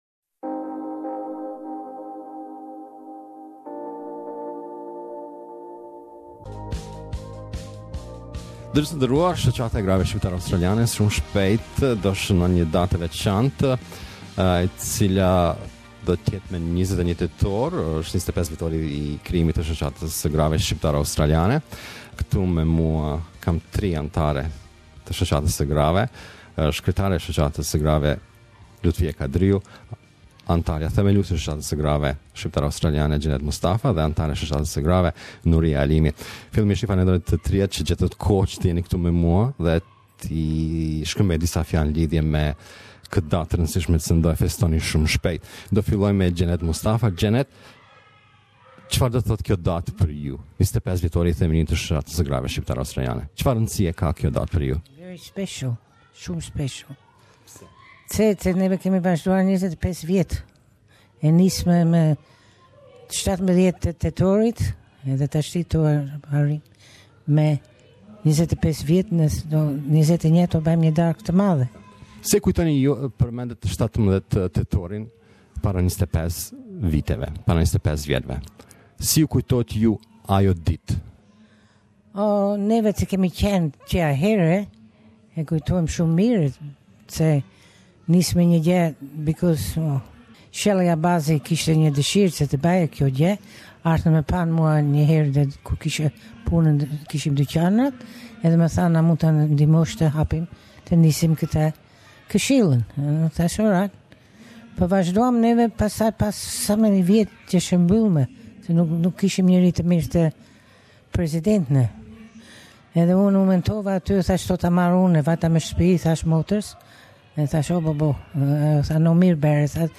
Weve interview them to learn about plans for 25th anniversary of the association.